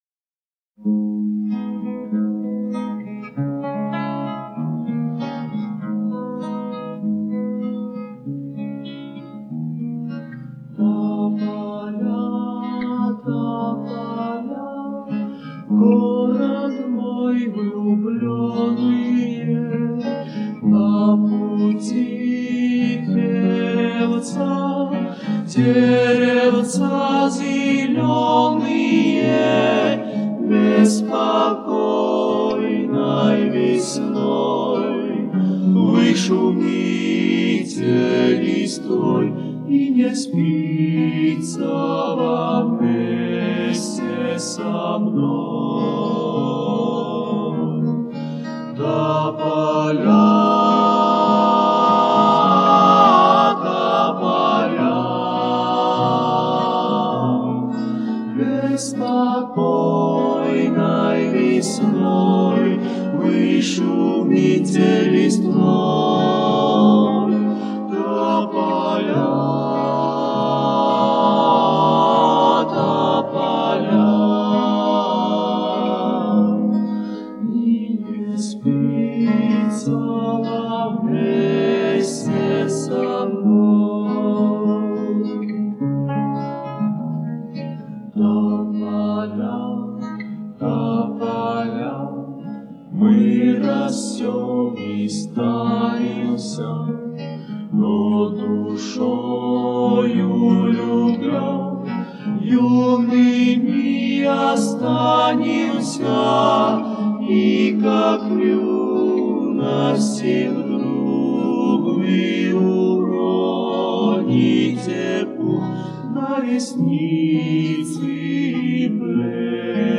парой наших грузинских песен